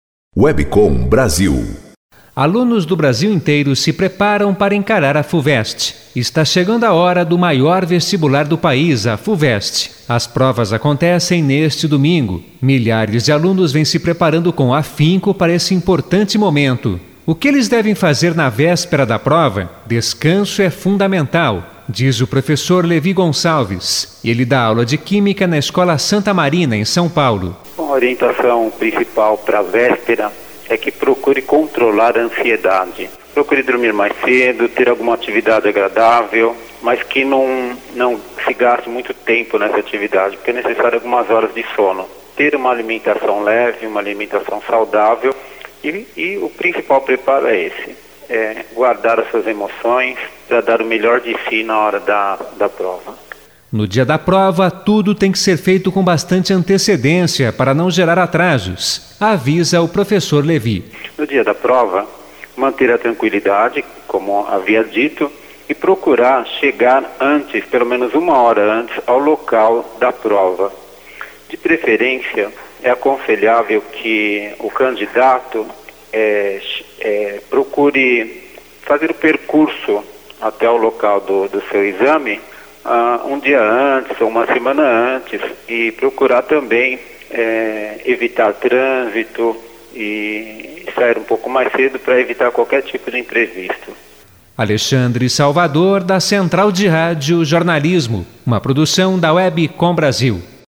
Confira alguns dos trabalhos da SUPRIR em rádios: Professores da Escola Santa Marina, concede entrevista para a Agência de Notícias WebcomBrasil, sobre a prova da FUVEST.